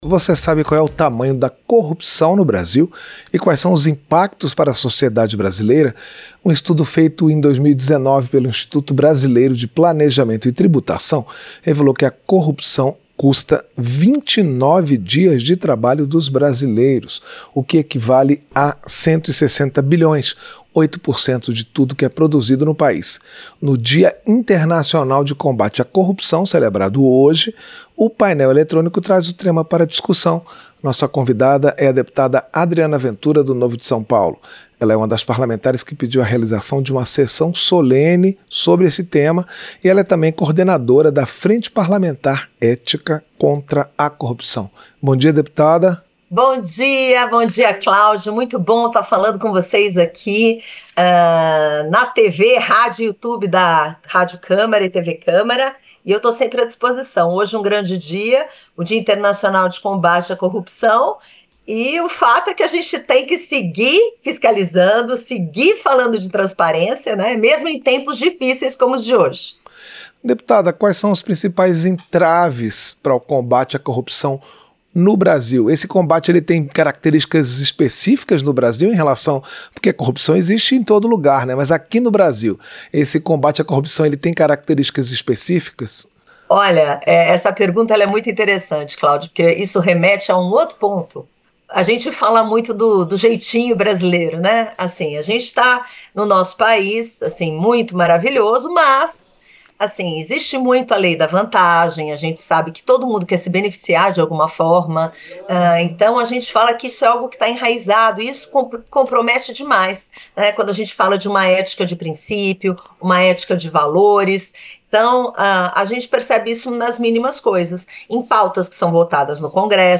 Entrevista - Dep. Adriana Ventura (Novo-SP)